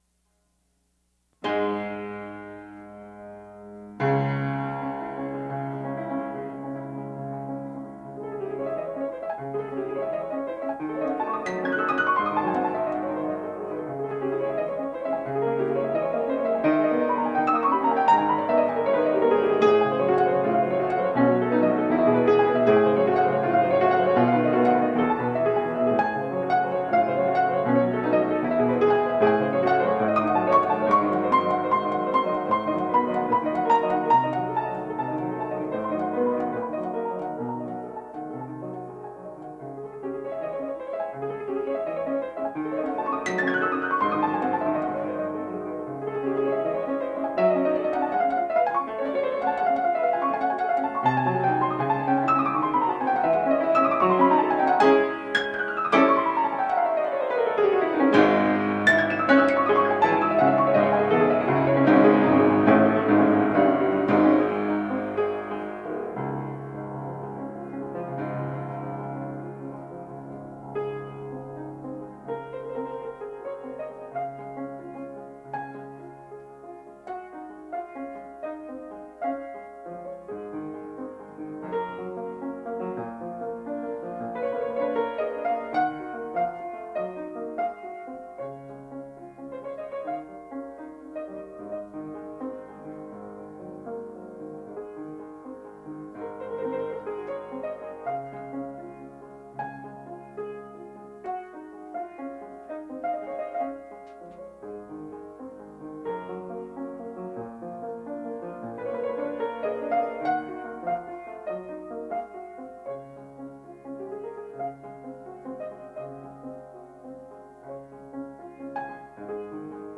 ・冒頭のAllegro agitateとは、速く（Allegro）＋agitato（激しく）。演奏の速さは♩=１６０程度、速く激しくコロコロパラパラ
・つなぎのLargoは、幅広く緩やかに。わずか２小節のその演奏速度は♩=６０程度に
・冒頭部が繰り返す後半部は、なんとPresto!。アレグロよりさらに速い♩=１８０程度でラストスパート！